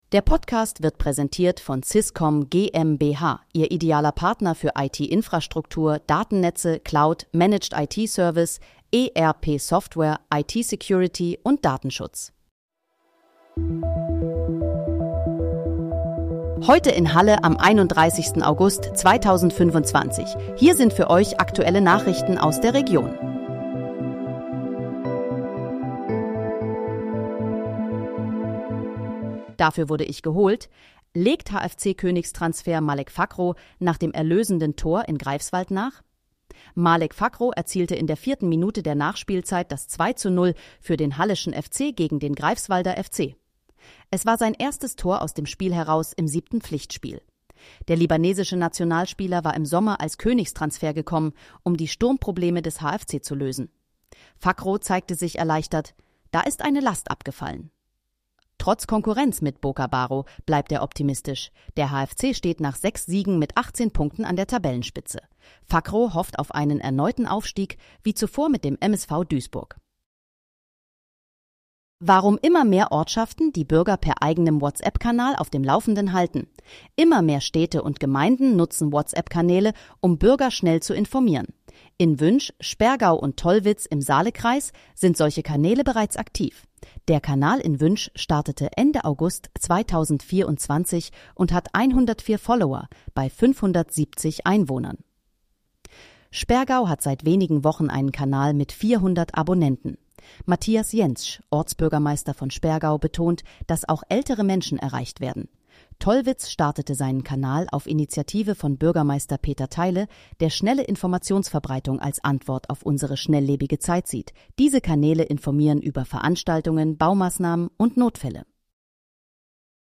Heute in, Halle: Aktuelle Nachrichten vom 31.08.2025, erstellt mit KI-Unterstützung
Nachrichten